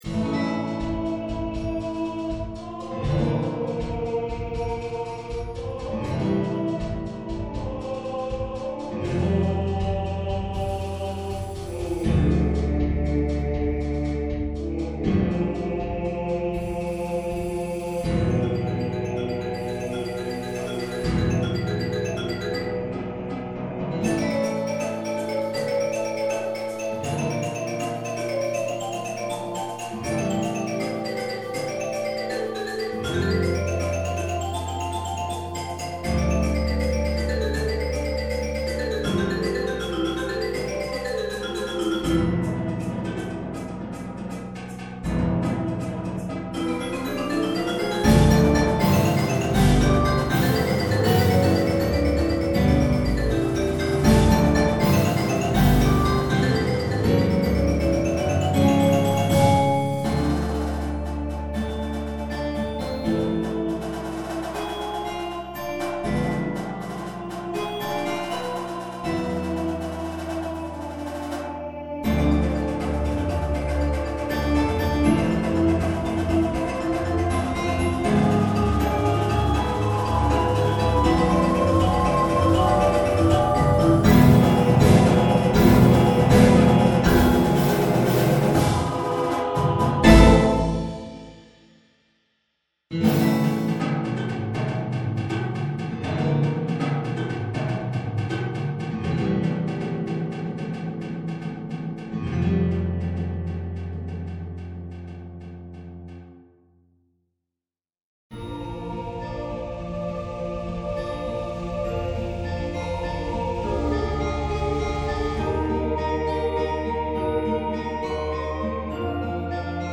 Upbeat Latin rhythms
Bells
Xylophone
Marimba (2)
Vibes
Synth
Guitar (2)
Electric Bass
Auxiliary Percussion (2)
Snare
Tenors (quints)
Bass Drums (5)
Cymbals